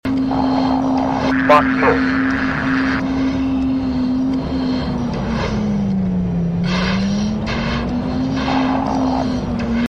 Every breath is randomly selected based on g-forces as well as missile tone and radio chatter.